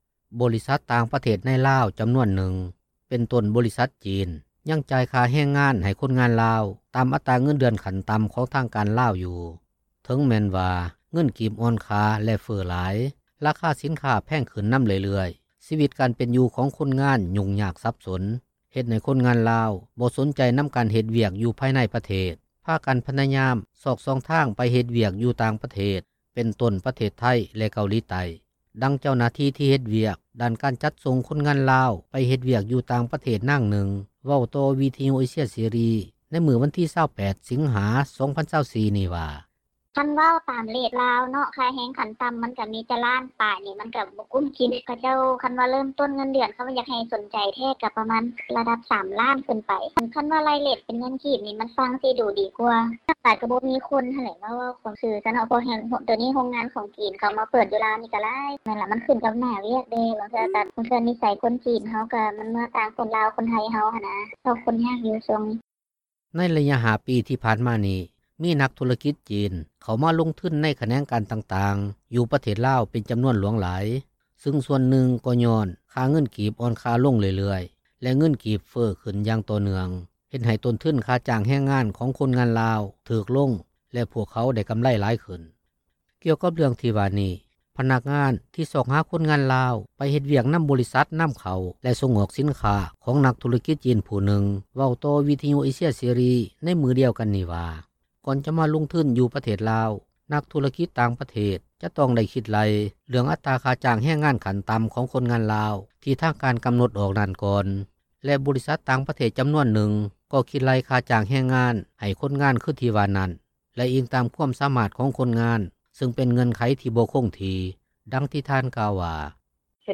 ບໍລິສັດຕ່າງປະເທດໃນລາວ ຈໍານວນນຶ່ງ ເປັນຕົ້ນບໍລິສັດຈີນ ຍັງຈ່າຍຄ່າແຮງງານ ໃຫ້ຄົນງານລາວ ຕາມອັດຕາເງິນເດືອນຂັ້ນຕໍ່າຂອງທາງການລາວຢູ່ ເຖິງແມ່ນວ່າ ເງິນກີບອ່ອນຄ່າ ແລະເຟີ້ຫຼາຍ, ລາຄາສິນຄ້າແພງຂື້ນນໍາເລື້ອຍໆ, ຊີວິດການເປັນຢູ່ຂອງຄົນງານ ຫຍຸ້ງຍາກສັບສົນ ເຮັດໃຫ້ຄົນງານລາວ ບໍ່ສົນໃຈນໍາການເຮັດວຽກຢູ່ພາຍໃນປະເທດ, ພາກັນພະຍາຍາມຊອກຊ່ອງທາງ ໄປເຮັດວຽກຢູ່ຕ່າງປະເທດ ເປັນຕົ້ນປະເທດໄທ ແລະເກົາຫຼີໃຕ້ ດັ່ງເຈົ້າໜ້າທີ່ ທີ່ເຮັດວຽກດ້ານການຈັດສົ່ງຄົນງານລາວ ໄປເຮັດວຽກຢູ່ຕ່າງປະເທດ ນາງນຶ່ງເວົ້າຕໍ່ວິທຍຸເອເຊັຍເສຣີ ໃນມື້ວັນທີ 28 ສິງຫາ 2024 ນີ້ວ່າ: